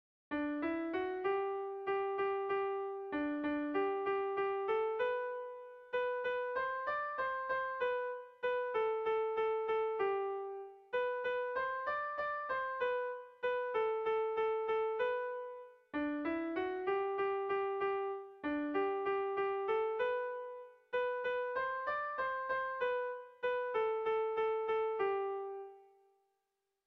Bertso melodies - View details   To know more about this section
Kontakizunezkoa
ABDAB